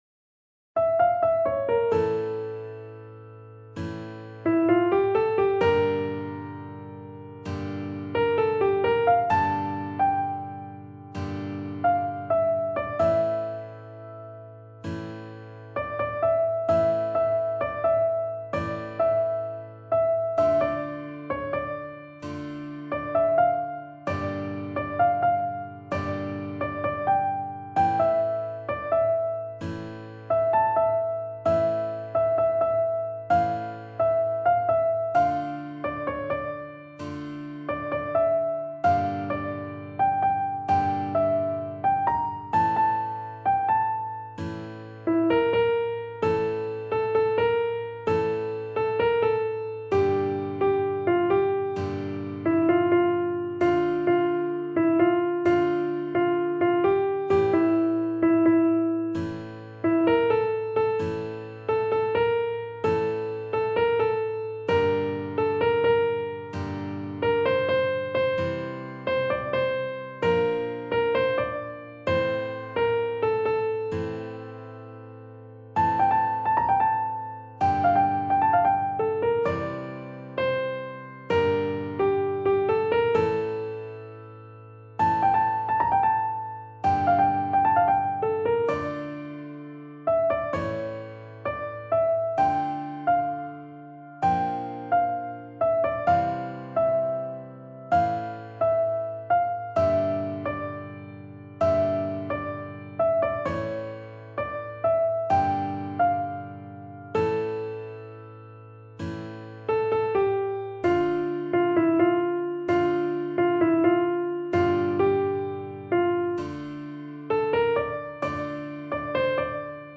نت کیبورد
• سطح نت : متوسط